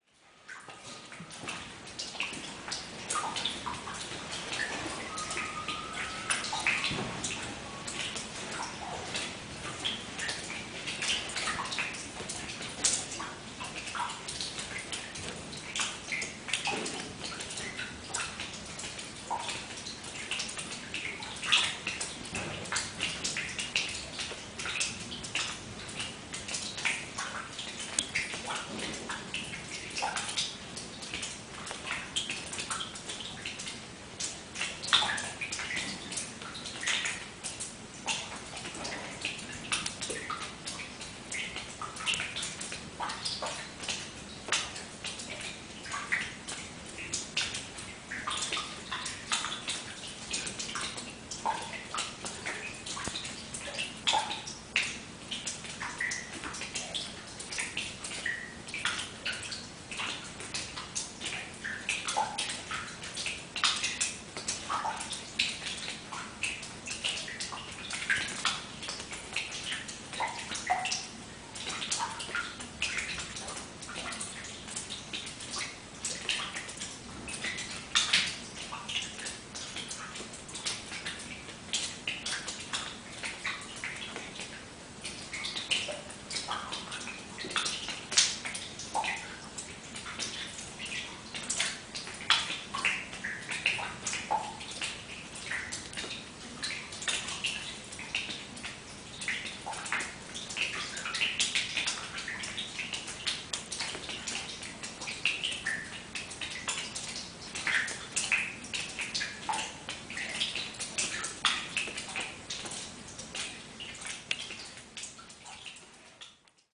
06-pissette.mp3